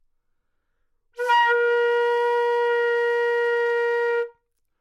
长笛单音（吹得不好） " 长笛 Asharp4 糟糕的攻击
描述：在巴塞罗那Universitat Pompeu Fabra音乐技术集团的goodsounds.org项目的背景下录制。单音乐器声音的Goodsound数据集。 instrument :: flutenote :: Asharpoctave :: 4midi note :: 58microphone :: neumann U87tuning reference :: 442goodsoundsid :: 3069 故意扮演恶意攻击的一个例子